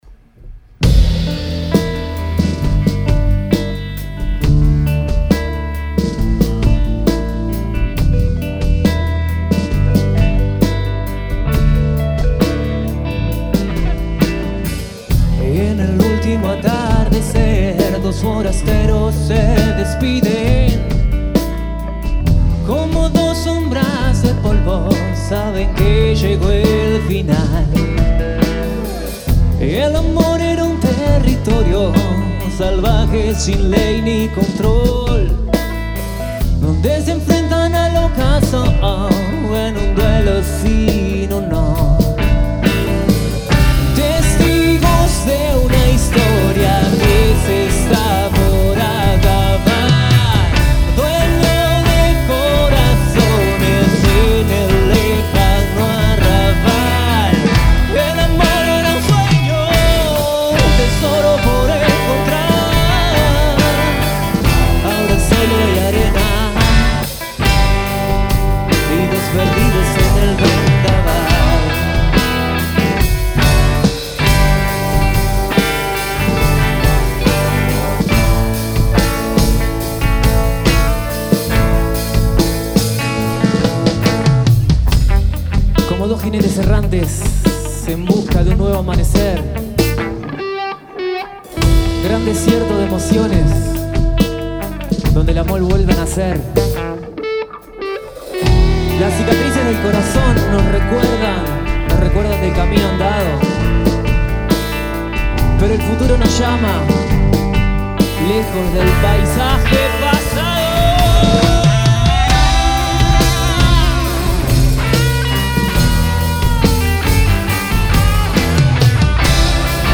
Grabada en vivo el 1 de junio de 2025
en Estudio del Monte